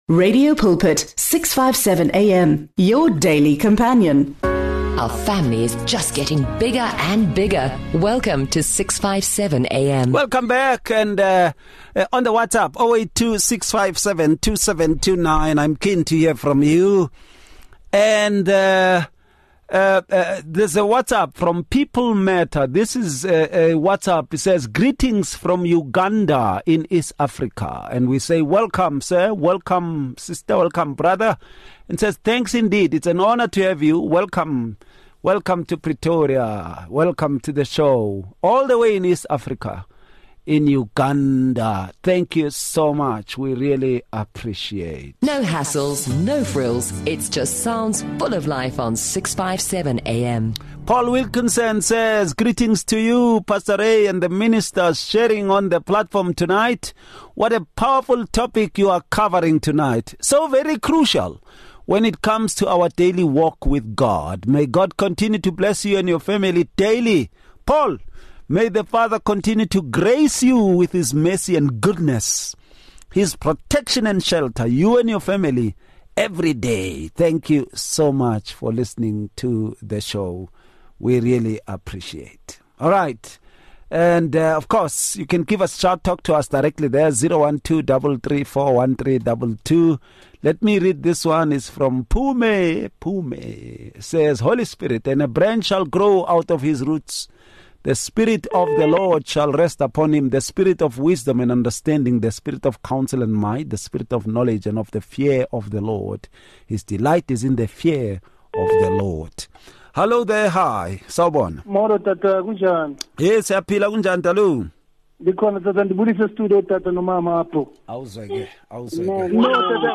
They discuss the Holy Spirit as the third Person of the Trinity, fully God and co-equal with the Father and the Son, emphasizing His nature rather than being an impersonal force. The panel highlights the Holy Spirit’s roles as Comforter, Helper, and life-giving agent who indwells believers, empowers spiritual growth, and applies the work of Christ in their lives.